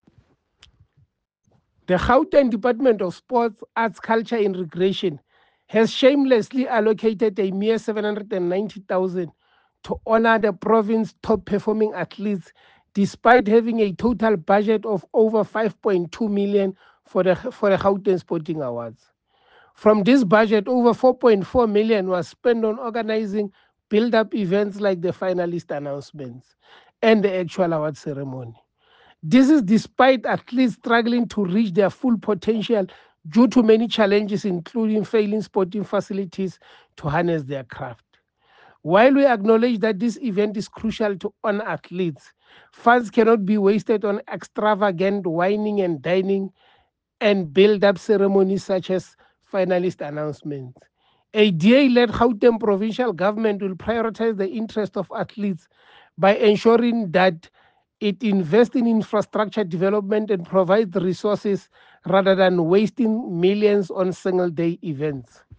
soundbite by Kingsol Chabalala MPL.